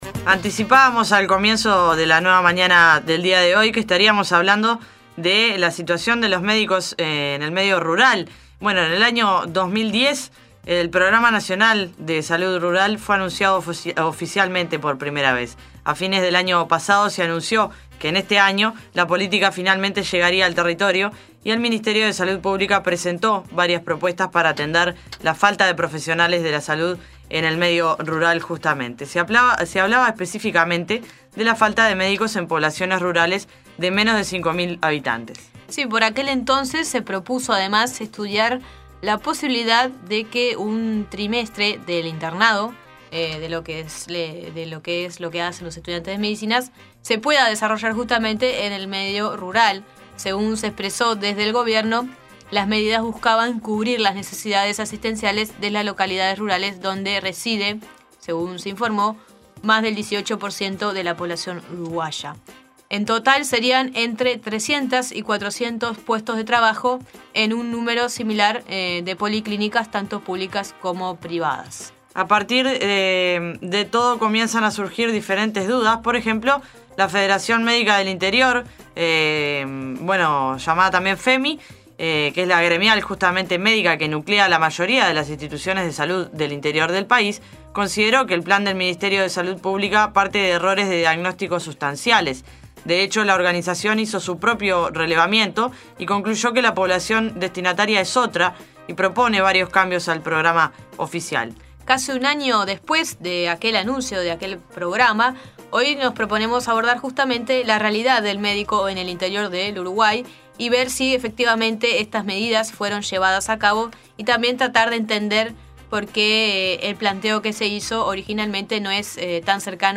Audio: Programa Nacional de Salud Rural. Entrevista